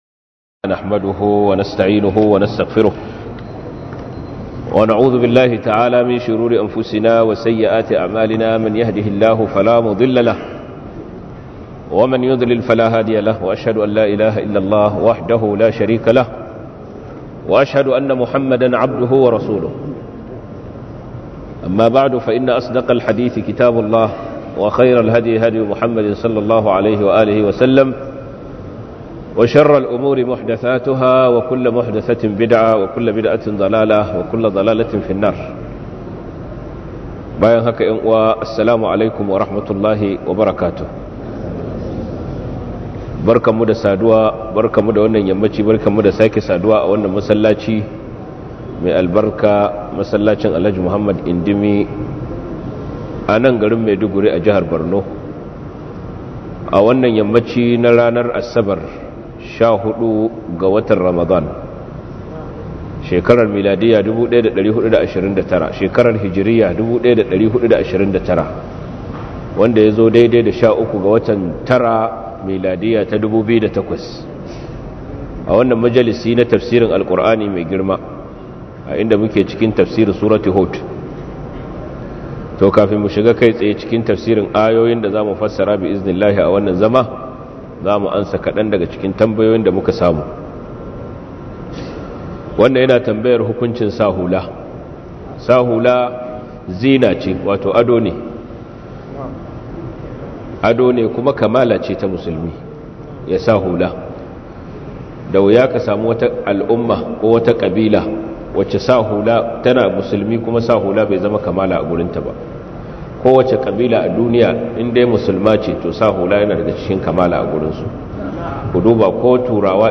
Tafsir 11: 12-14